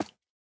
1.21.5 / assets / minecraft / sounds / mob / frog / step1.ogg